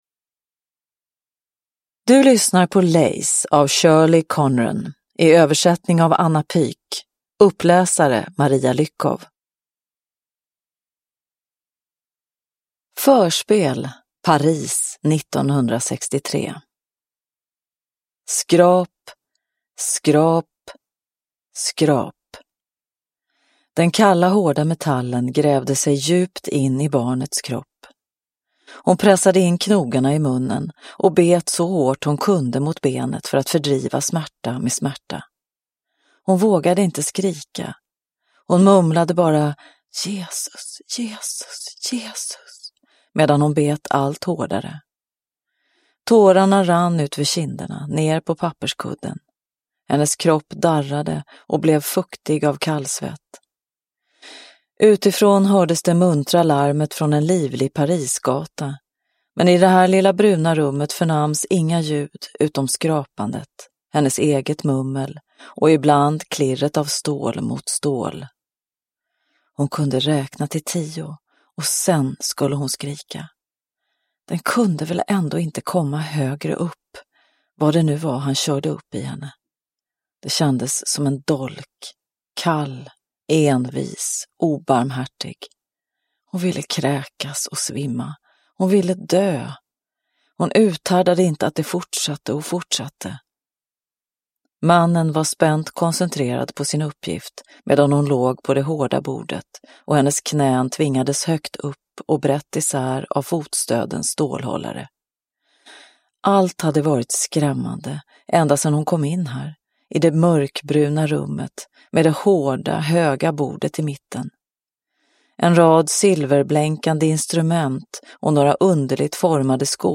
Lace – Ljudbok – Laddas ner